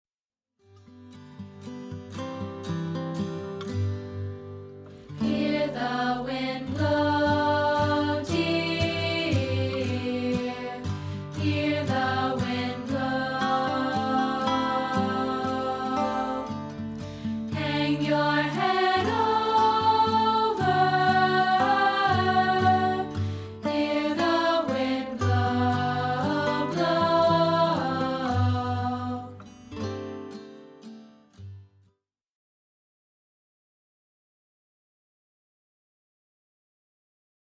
To help teach part 2 of our arrangement of this folk tune
This is part 2's first verse.